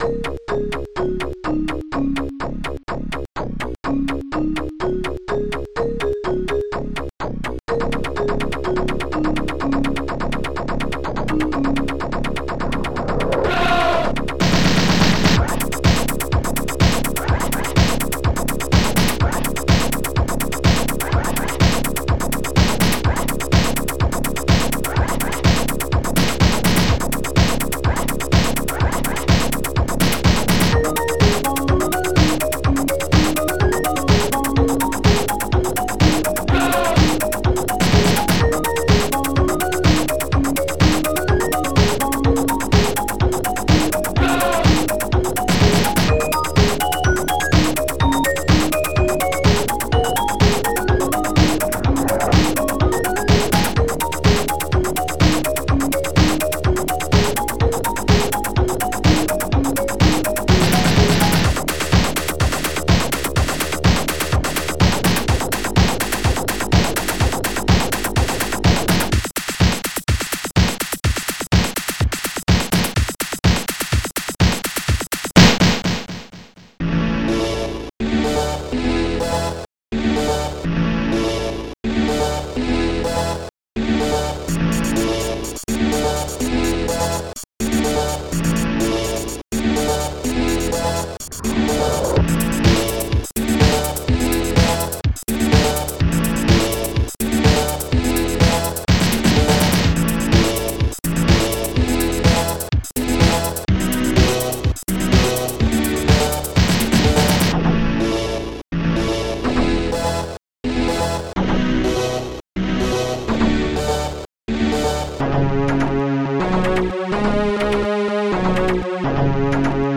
marimba
shaker
starsnare
bassdrum4
technobass2
hihat6
st-01:scratching1
strings4